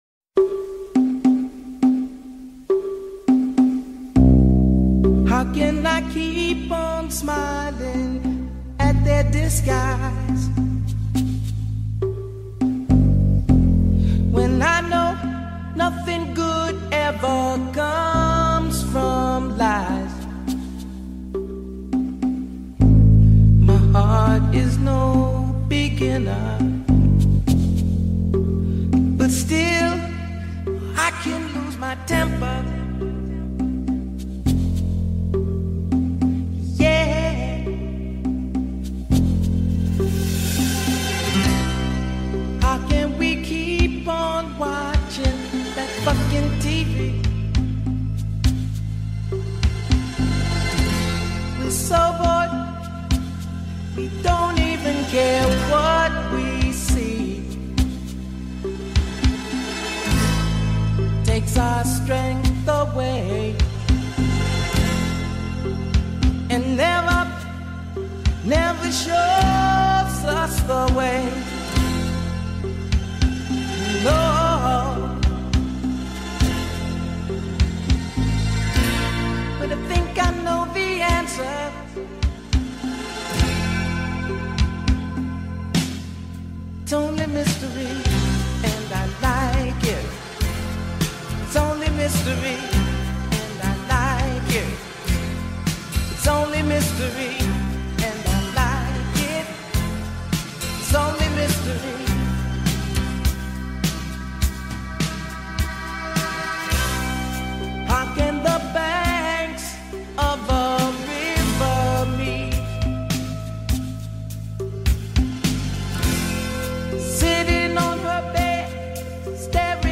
103 Bpm - Key : B